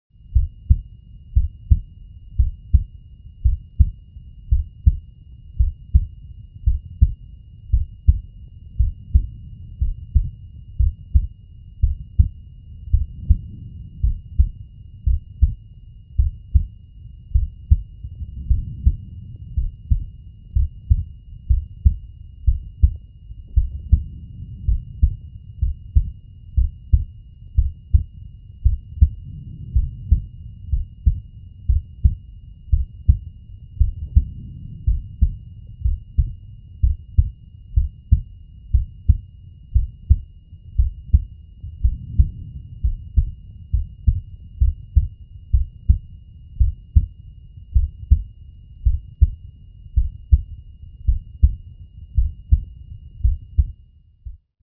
دانلود صدای قلب انسان با ریتم آهسته از ساعد نیوز با لینک مستقیم و کیفیت بالا
جلوه های صوتی